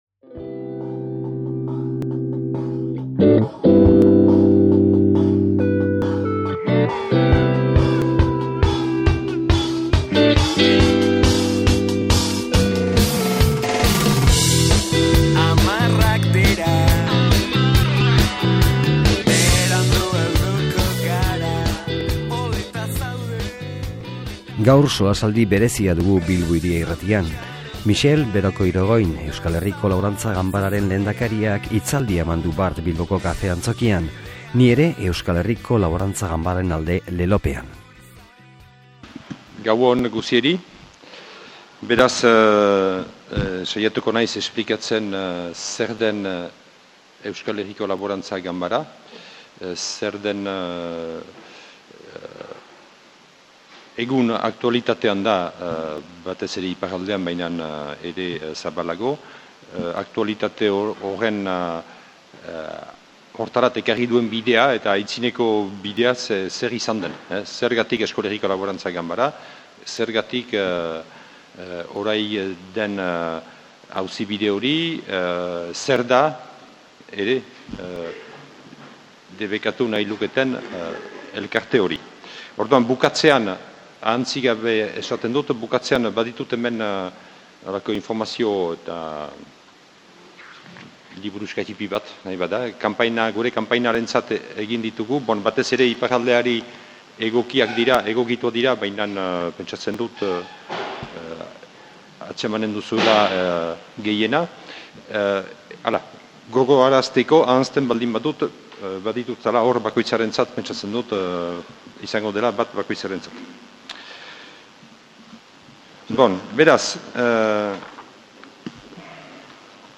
Bilboko Kafe Antzokian emaniko hitzaldia hain zuzen.